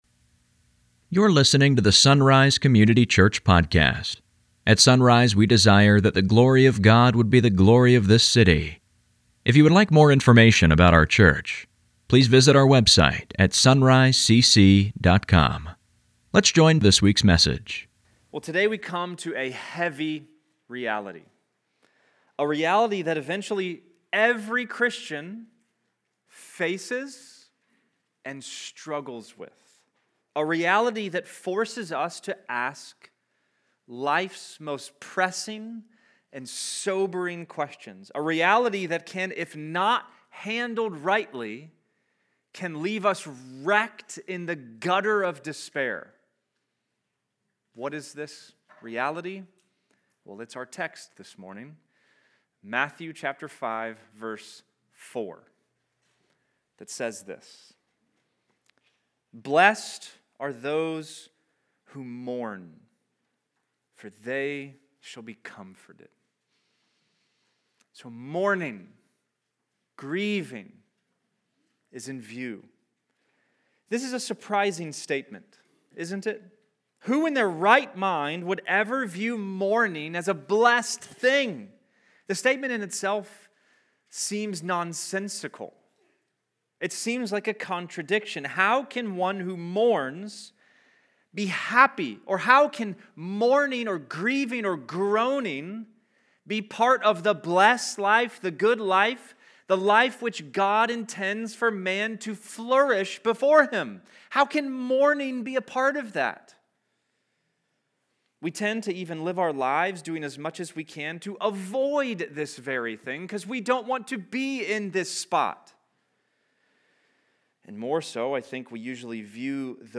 Sunday Mornings | SonRise Community Church
This is our goal, we don’t do it perfectly, but we do aim to be faithful handlers of God’s Word.[1] Pray with me as we begin…